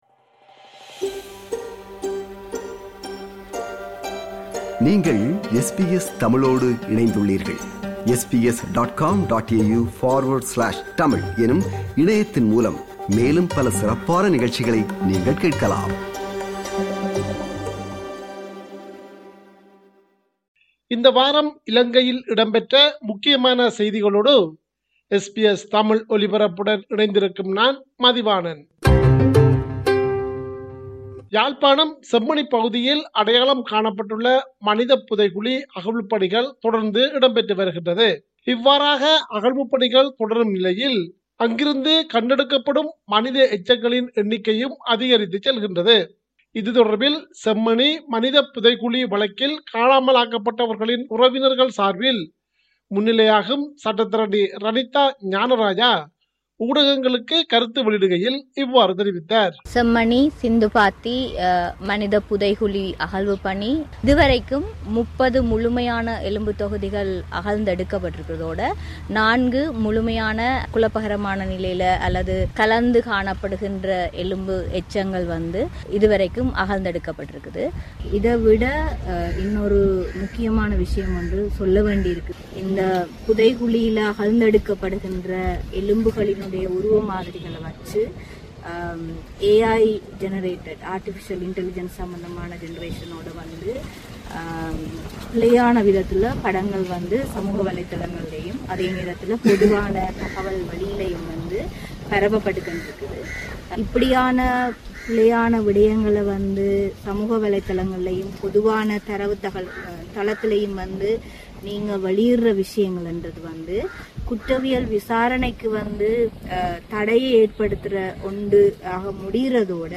Top news from Sri Lanka this week To hear more podcasts from SBS Tamil, subscribe to our podcast collection.